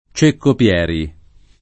Ceccopieri [ © ekkop L$ ri ] cogn.